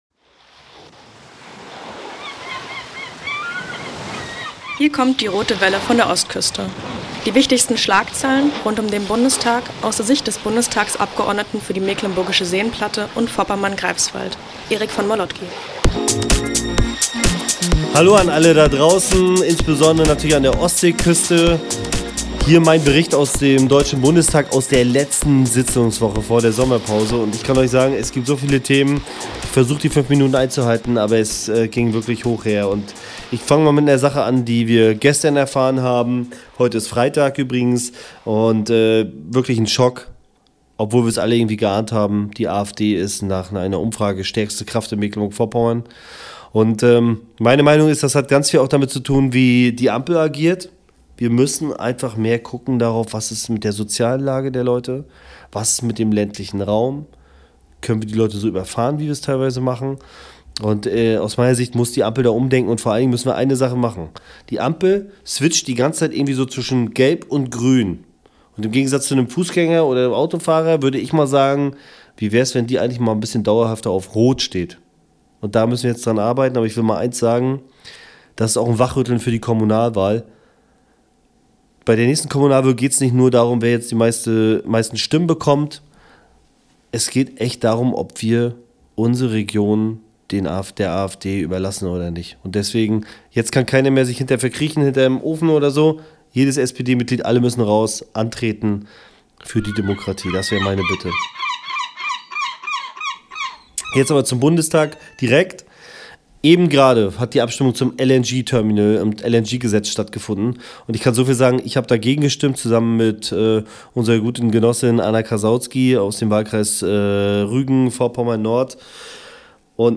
Heute sendet Erik direkt aus dem Bundestag, wo er eine hitzige Sitzungswoche hinter sich hat.